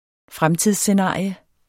Udtale [ ˈfʁamtiðs- ]